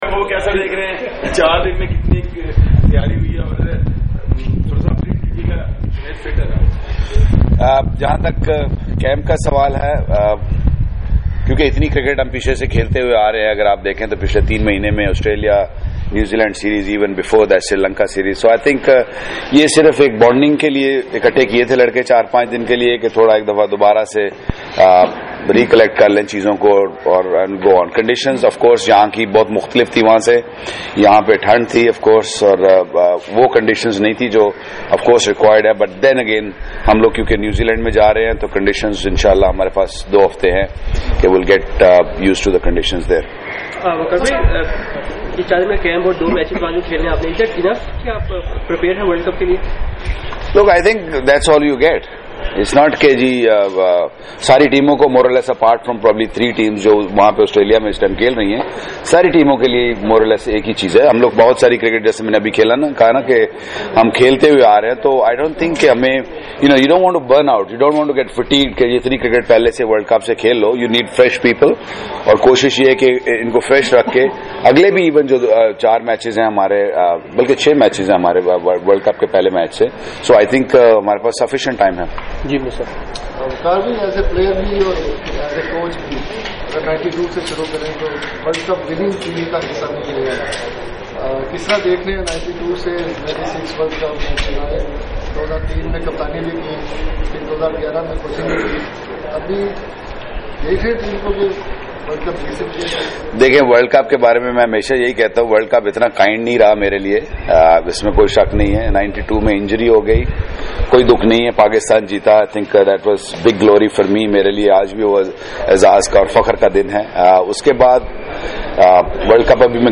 Waqar Younis Media talk at Gaddafi Stadium Lahore